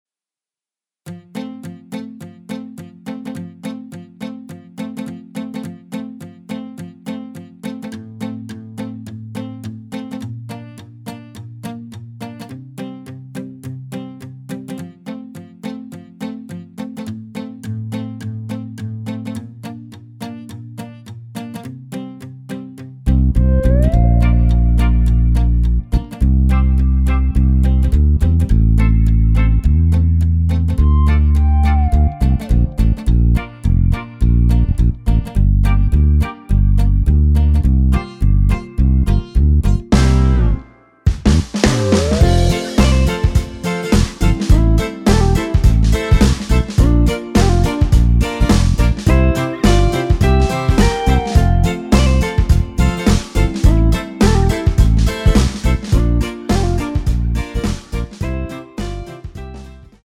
대부분의 여성분이 부르실수 있는키로 제작 하였습니다.(미리듣기 참조)
앞부분30초, 뒷부분30초씩 편집해서 올려 드리고 있습니다.